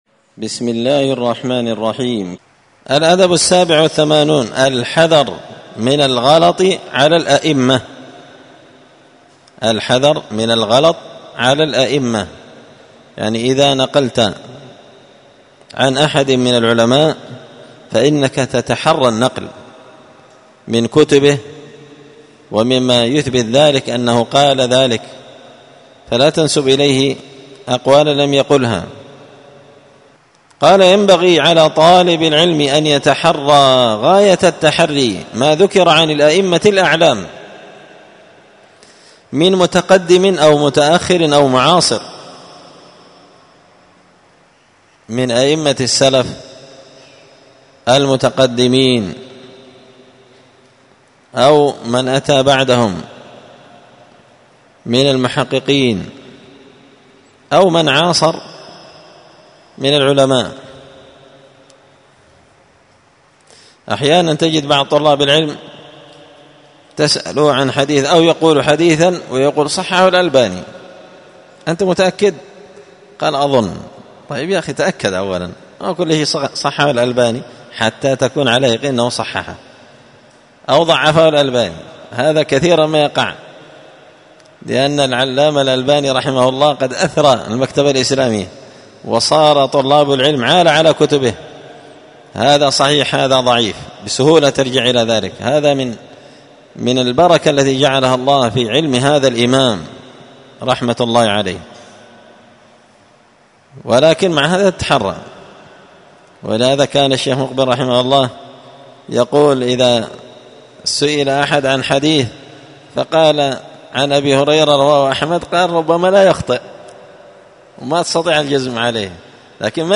الدرس السابع والتسعون (97) الأدب السابع والثمانون الحذر من الغلط على الأئمة